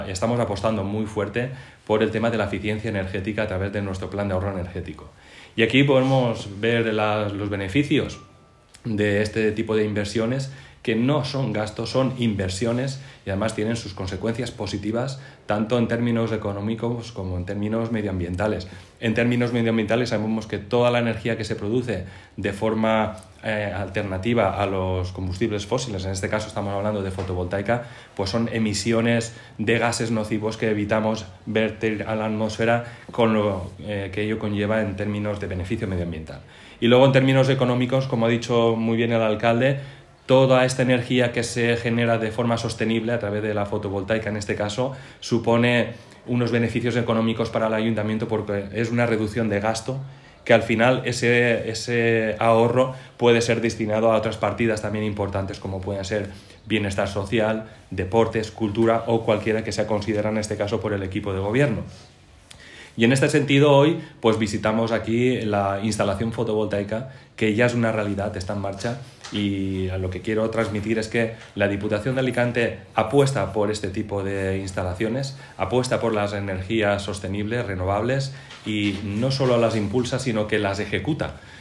Inversiones-Medio-Ambiente-Aspe-CORTE-Miguel-Angel-Sanchez.m4a